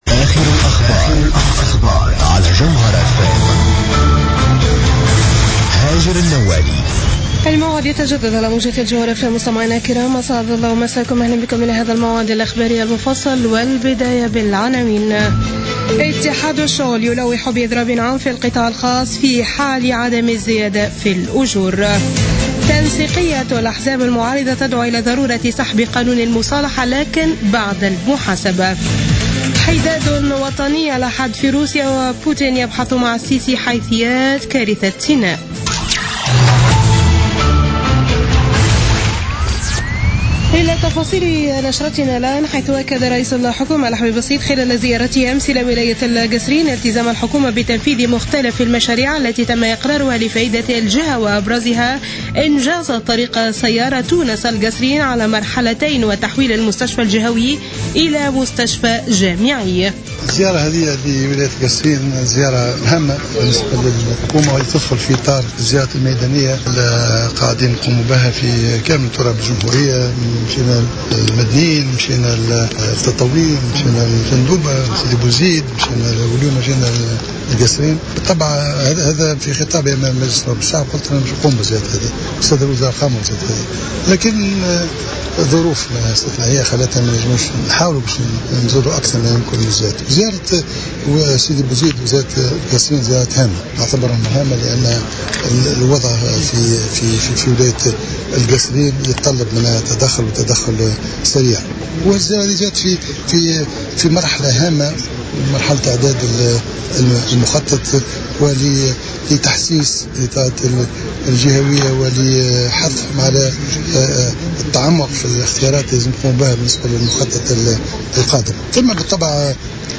نشرة أخبار منتصف الليل ليوم الأحد 1 نوفمبر 2015